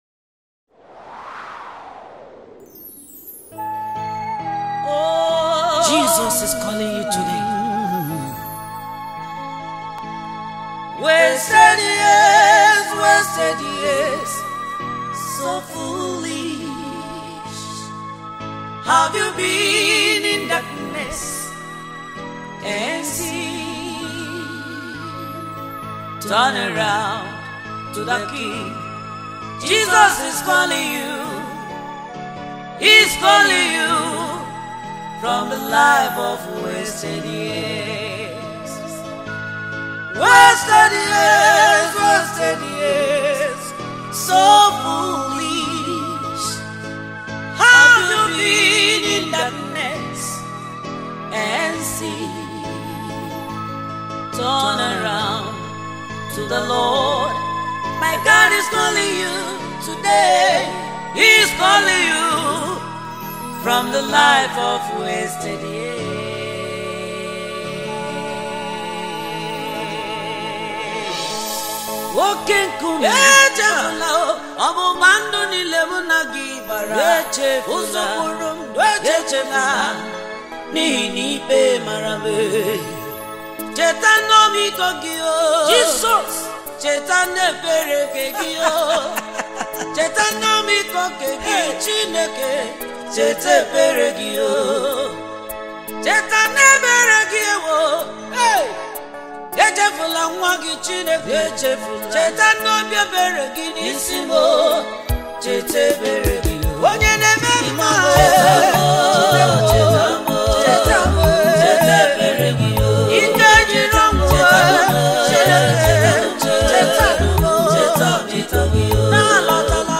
Igbo Gospel music
worship single